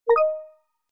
Teams Ping.mp3